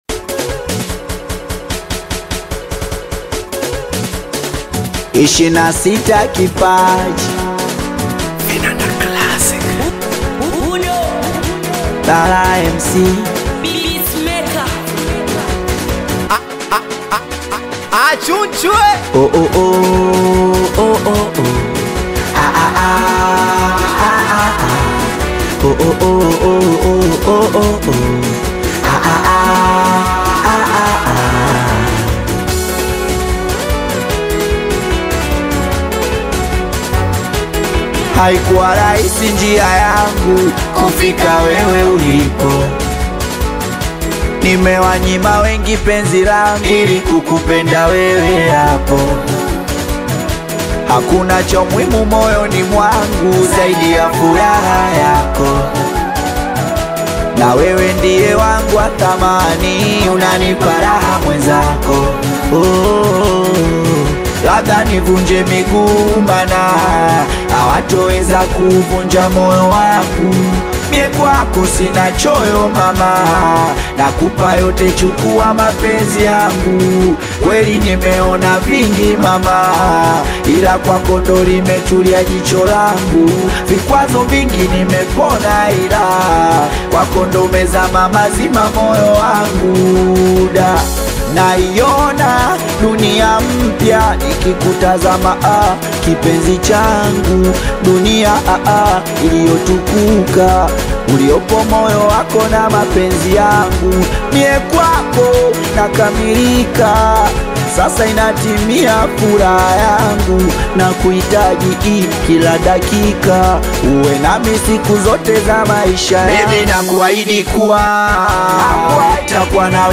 captivating Afro-pop/Singeli single
Genre: Singeli